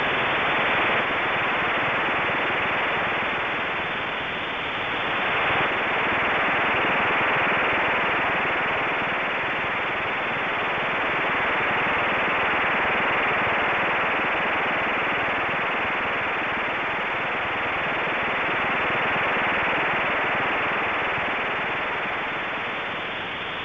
STANAG 4285